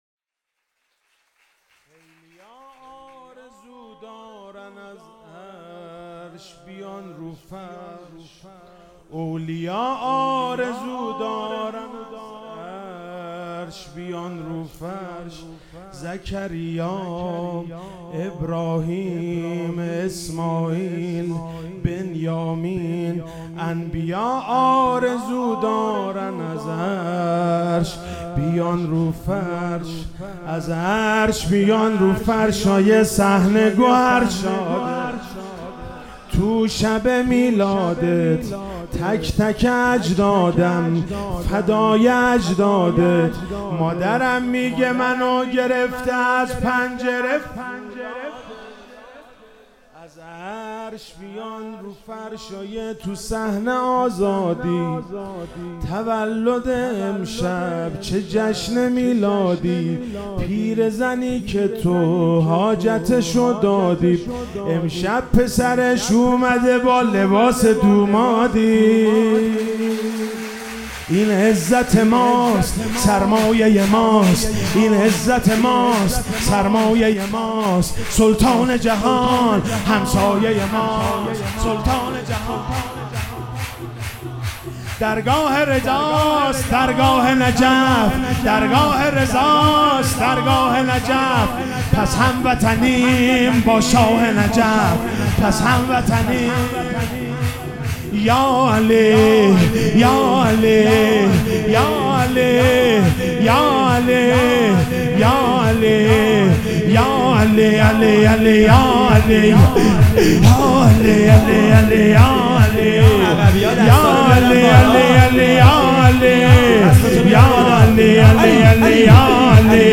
مداحی به سبک مدح اجرا شده است.